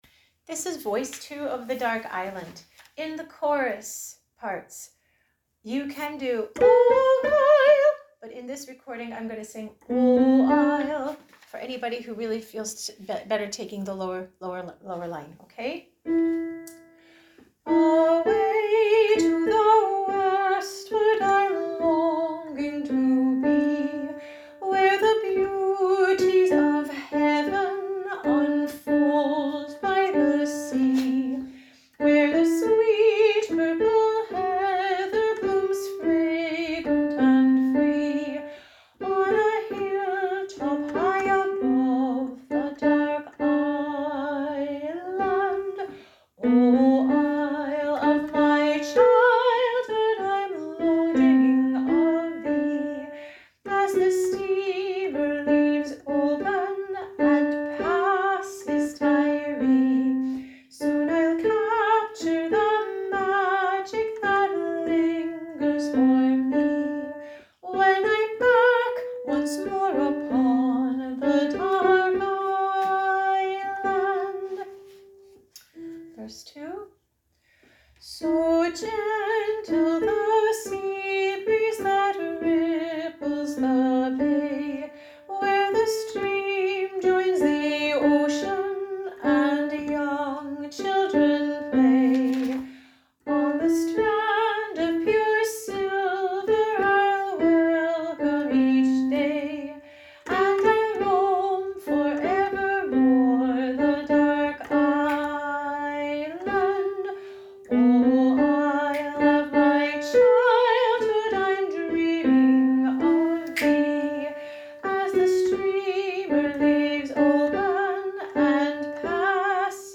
The Dark IslandDownload The Dark Island - lyrics only Voice Sing-Alongs: The Dark Island Voice 1 The Dark Island Voice 2 The Dark Island Voice 3
the-dark-island-voice-2.mp3